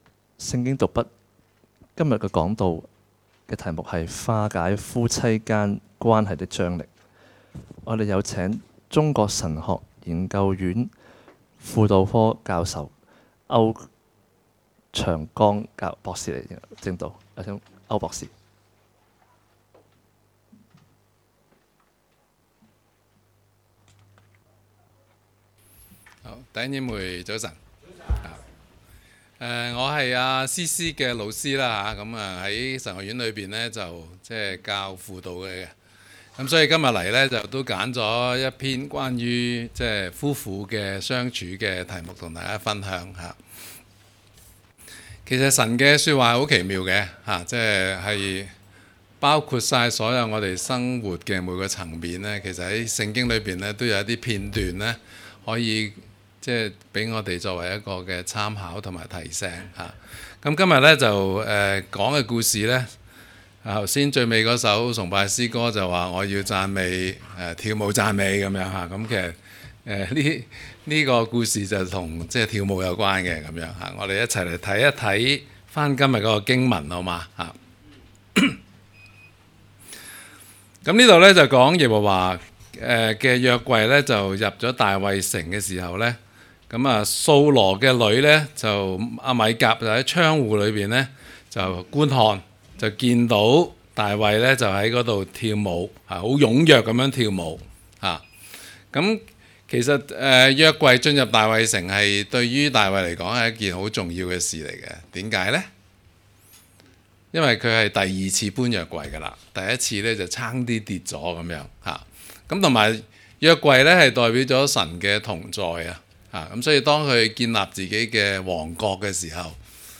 講道 ： 化解夫婦間關係的張力